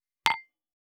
283,食器をぶつける,ガラスをあてる,皿が当たる音,皿の音,台所音,皿を重ねる,カチャ,ガチャン,カタッ,コトン,ガシャーン,カラン,カタカタ,チーン,
コップ